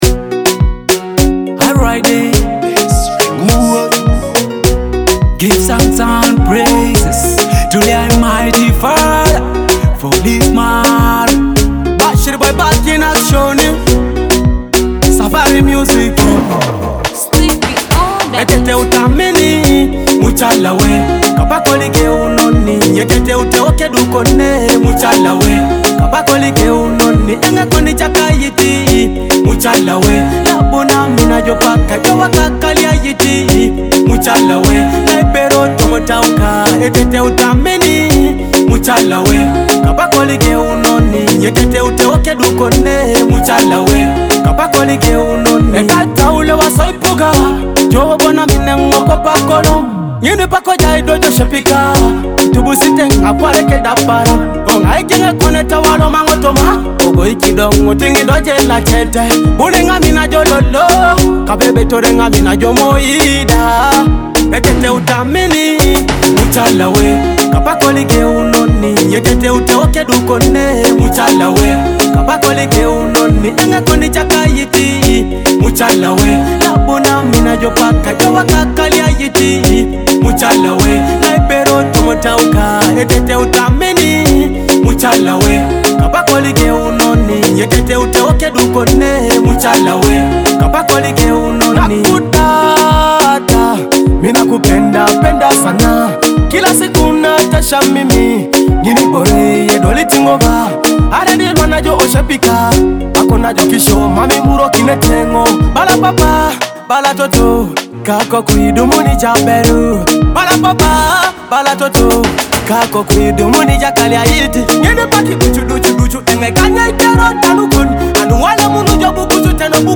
With catchy beats and soulful lyrics